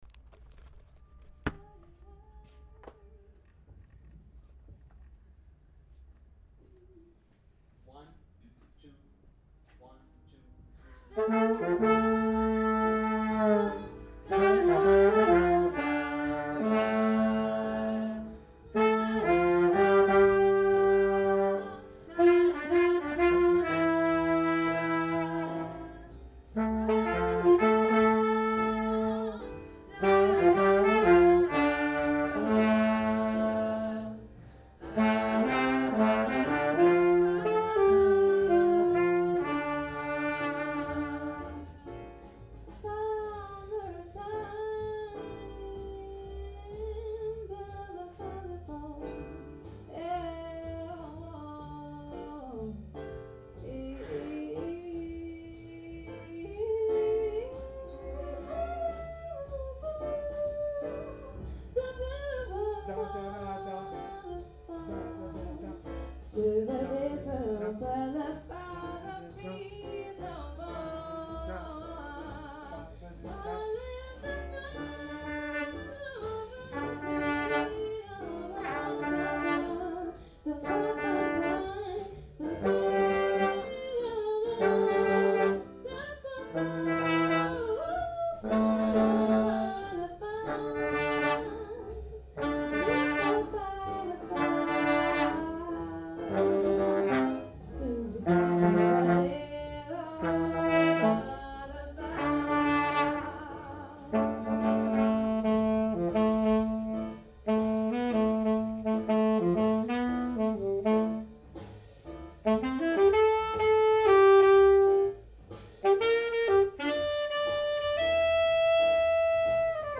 at the 2009 (19th Annual) Pulawy International Jazz Workshop
Combo/Improvisation Rehearsal Summer... with Jamey Aebersold accompaniment recorded with Elevation at::